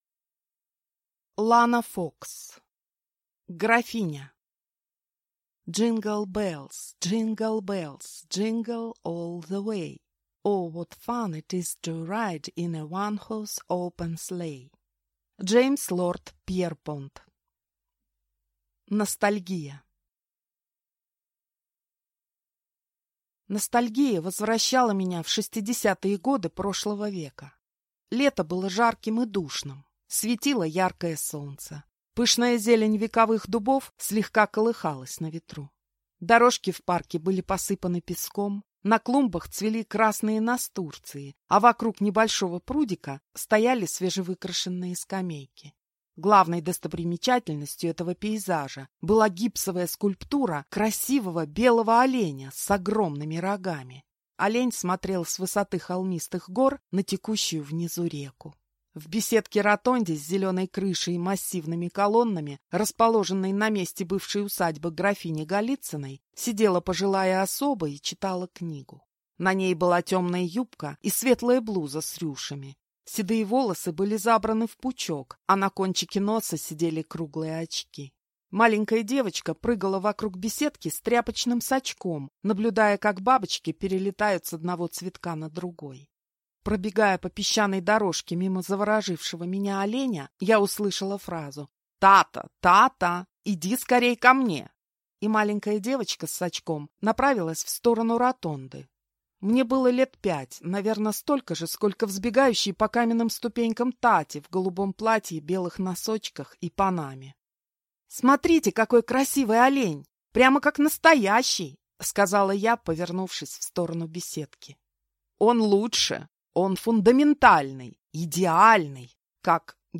Аудиокнига Графиня | Библиотека аудиокниг